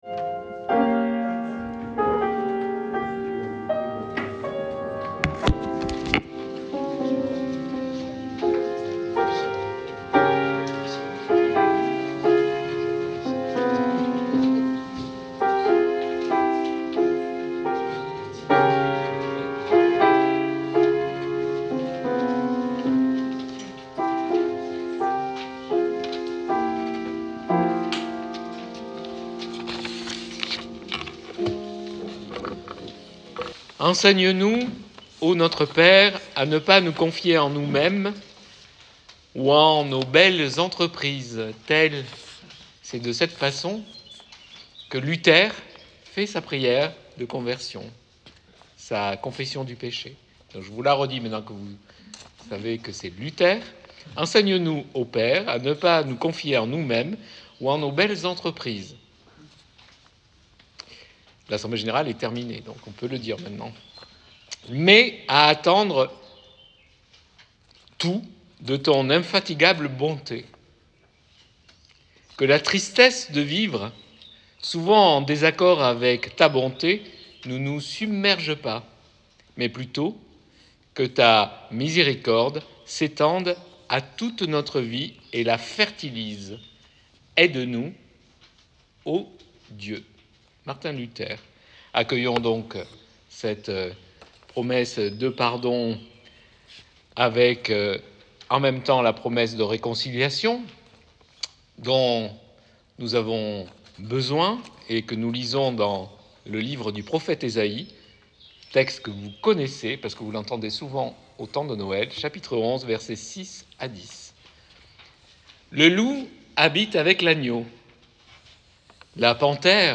LE 26 mai 2024. AG, CULTE À LA MAISON FRATERNELLE
Prédication 26 mai 2024.mp3 (31.84 Mo)